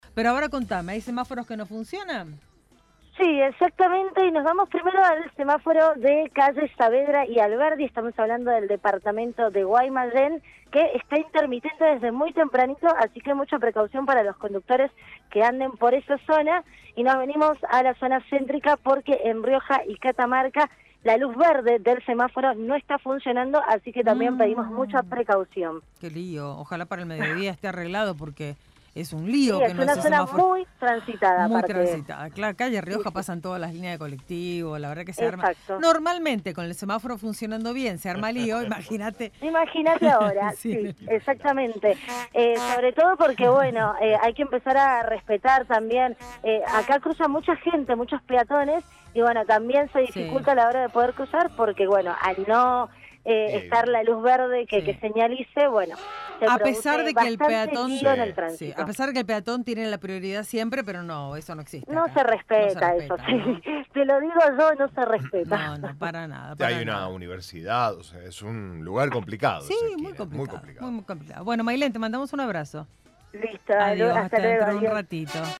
LVDiez - Radio de Cuyo - Móvil de LVDiez- semáforos sin funcionar en Guaymallén y Ciudad